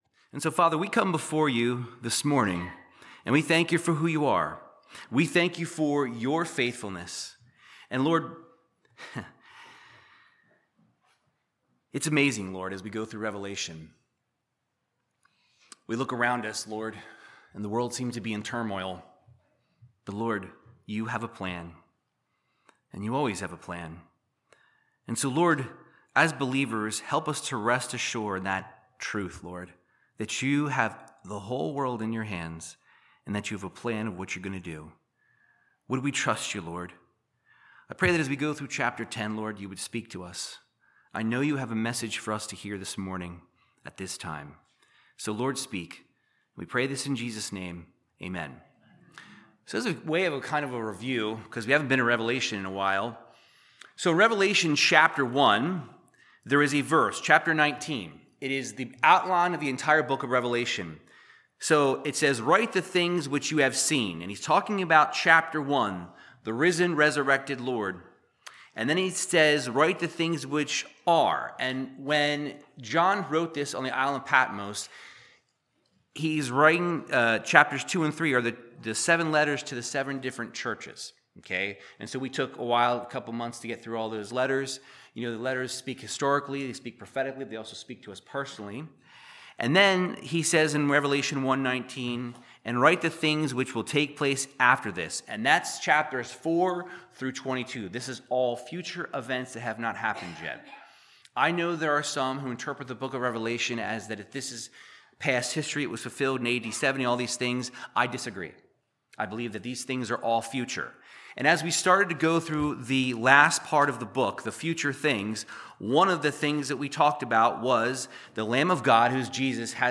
Verse by verse Bible teaching through the book of Revelation chapter 10